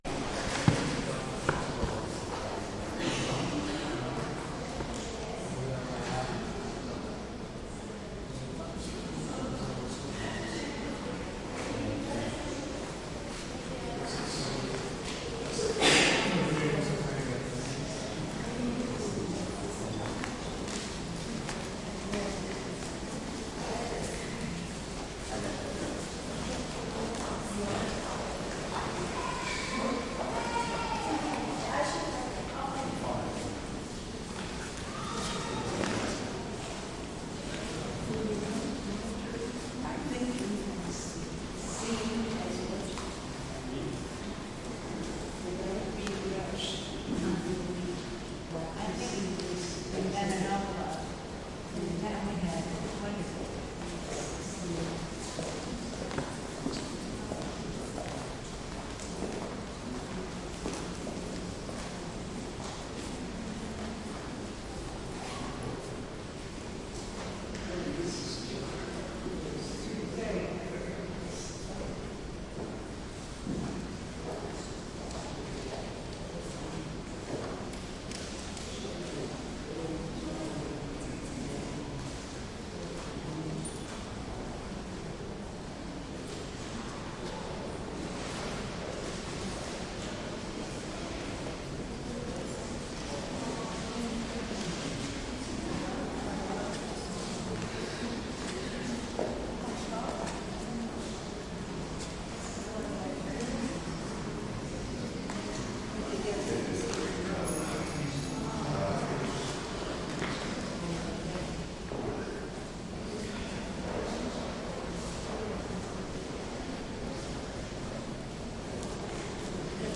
描述：录音是在德国莱比锡的艺术博物馆（Museum der bildenden künste）的主门厅进行的，从门厅上方的画廊拍摄，距离地面约15米。
标签： 城市 室内 大厅 环境 大房间 博物馆 现场录音
声道立体声